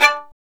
Index of /90_sSampleCDs/Roland - String Master Series/STR_Violin 2&3vb/STR_Vln3 _ marc